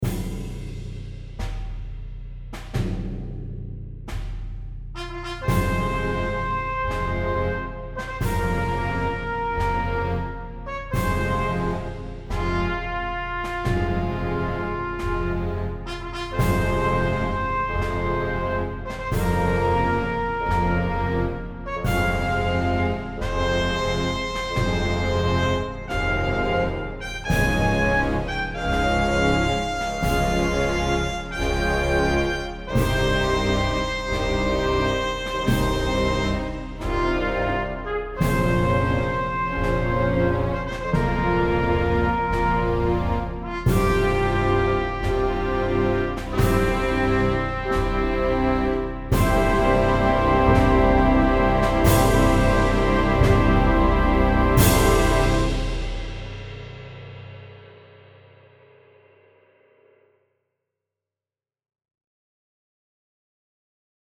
再生する凝縮された高密度なsoundに思わず惹き込まれる--繰り返される一定の波形が王冠を巡る壮絶な歴史を連想させる--狂気渦まく戦闘シーン--火刑に処されるジャンヌの果てしない苦悩と無念さ--天国に召されていく魂...祈り..